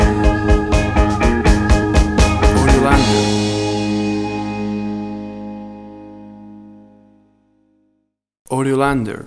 WAV Sample Rate: 16-Bit stereo, 44.1 kHz
Tempo (BPM): 164